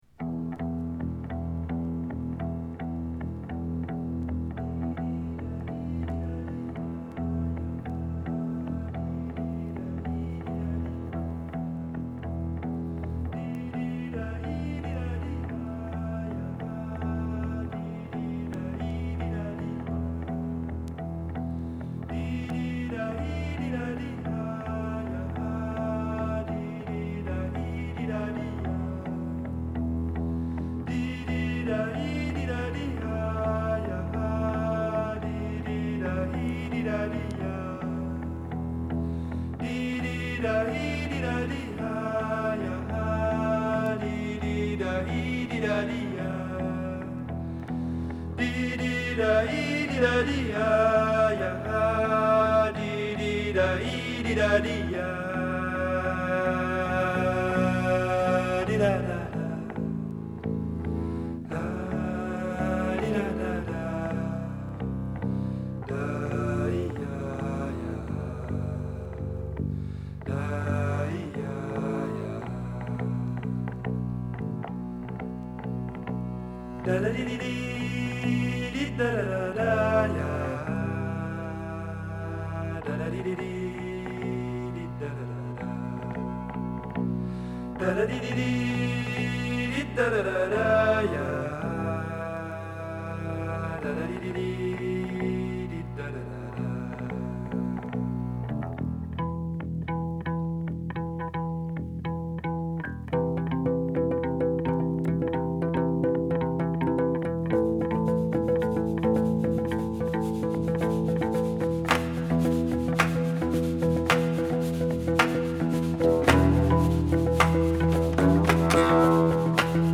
bass, chanting, and claps make up this artsy number